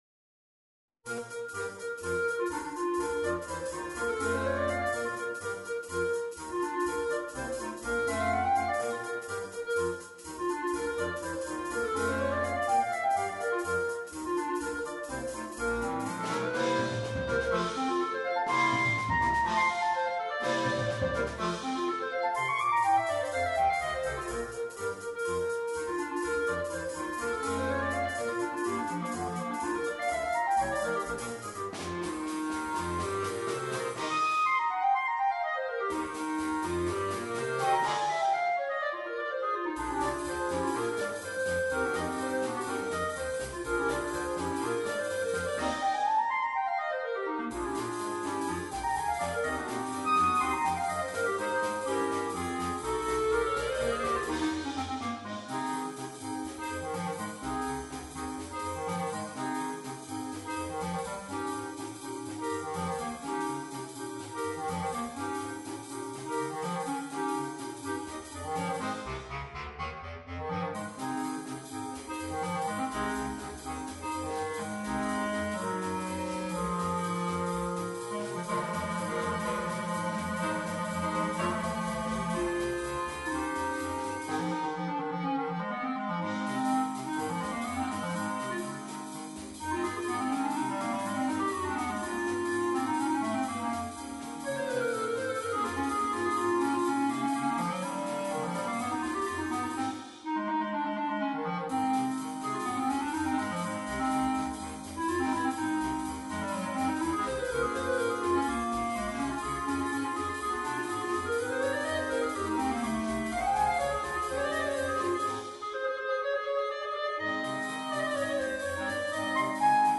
clarinetto
5 Clarinetti